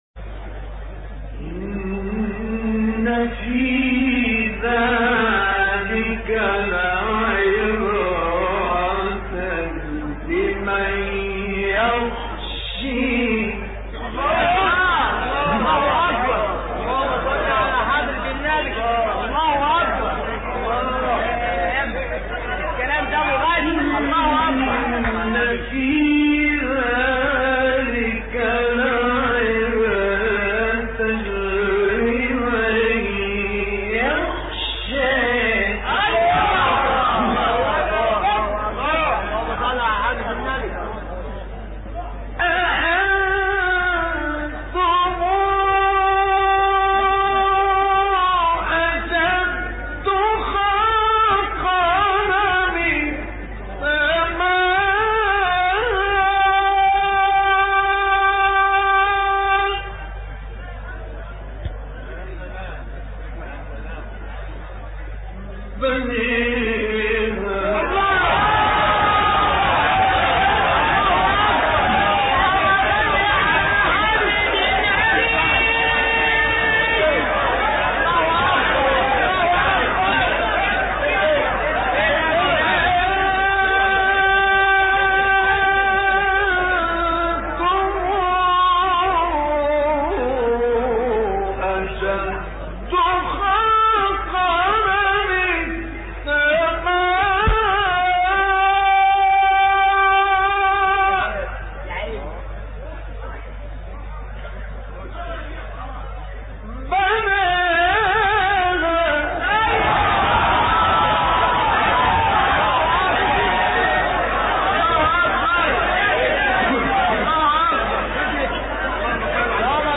حیات اعلی :: دریافت 9- نازعات = بوستان تلاوت 98